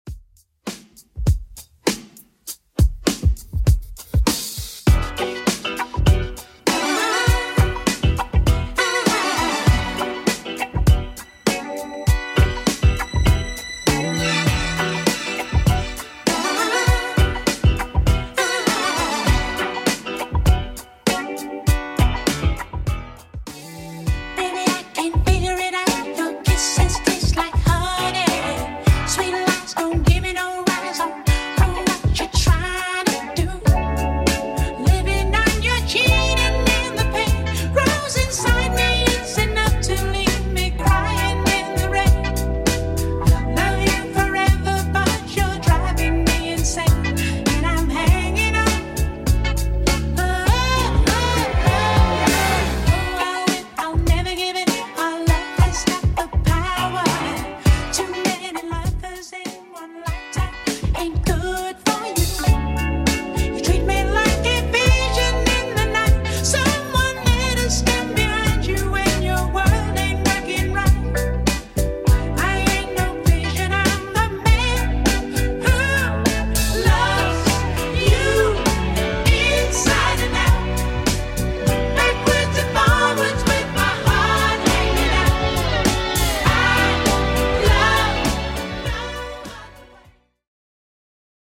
Genre: 80's
BPM: 107